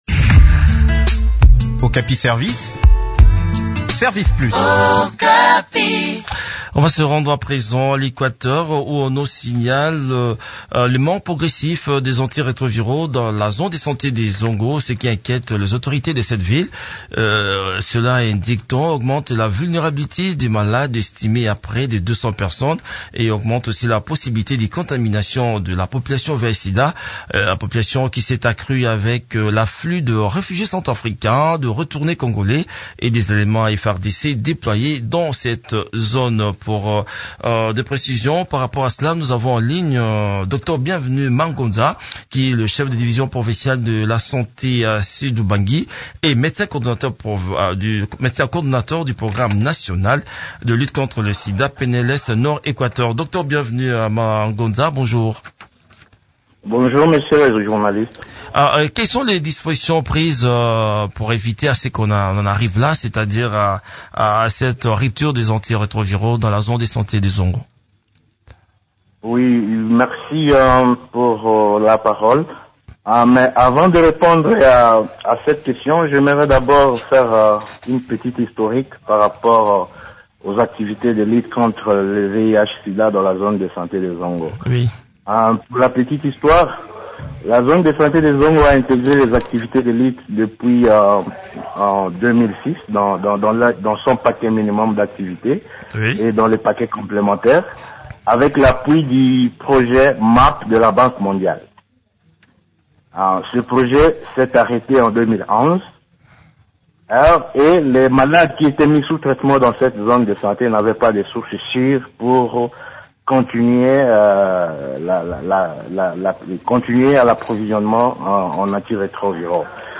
s’est entretenu avec